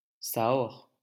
Sahorre (French pronunciation: [sa.ɔʁ]